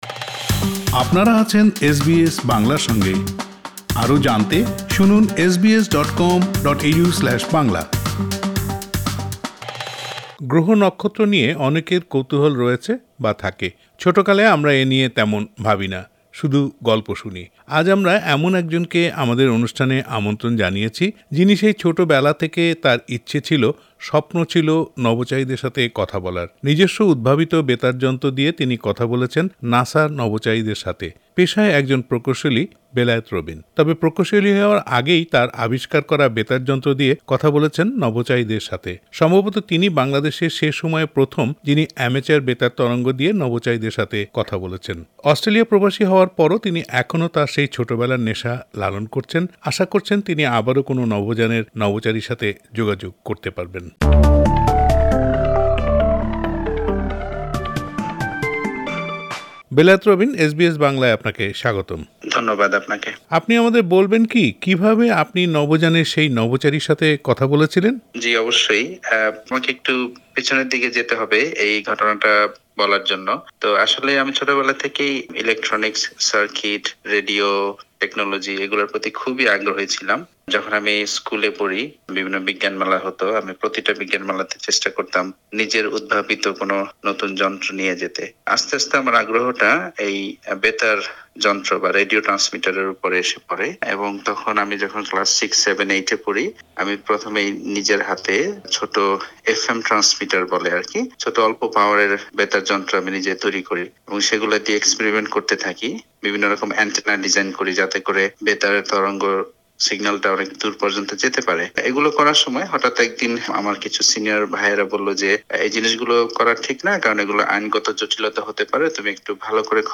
আজ আমরা এমন একজনকে আমাদের অনুষ্ঠানে আমন্ত্রণ জানিয়েছি।